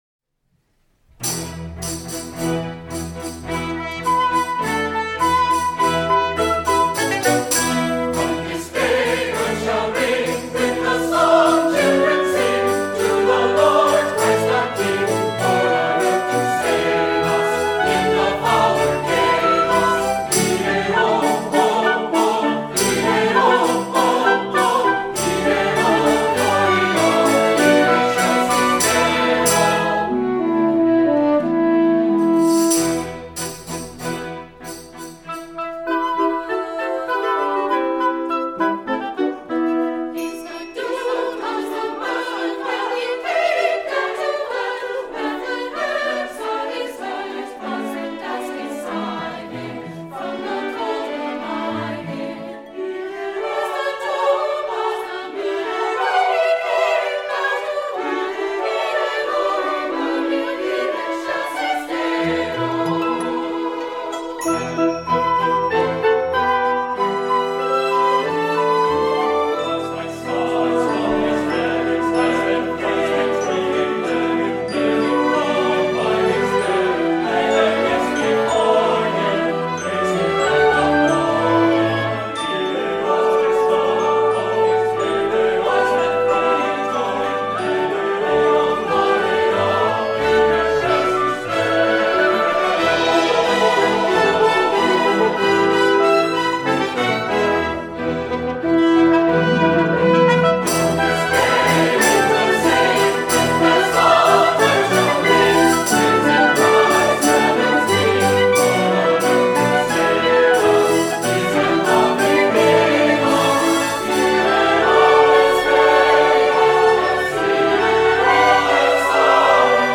CHAMBER Orchestra & Chorus
(An arrangement of the ancient carol)
BRASS:  1 Horn, 1 Trumpet in C
HARP:  1 Harp